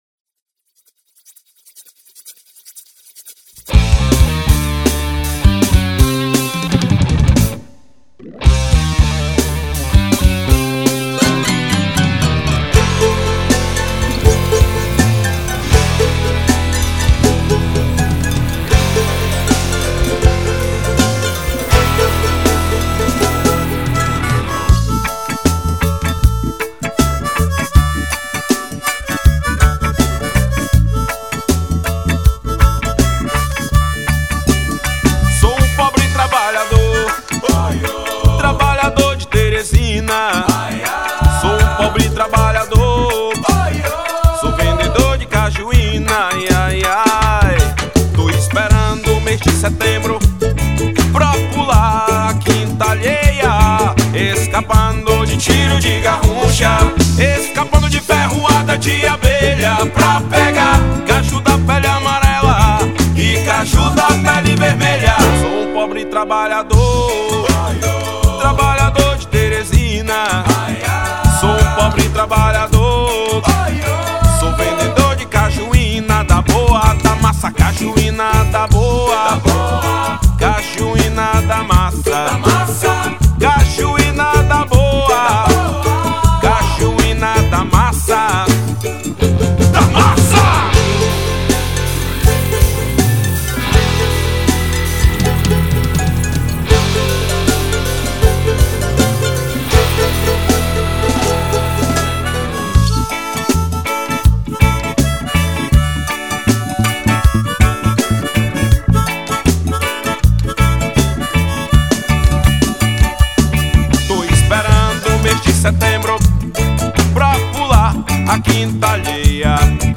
2510   03:40:00   Faixa: 2    Rock Nacional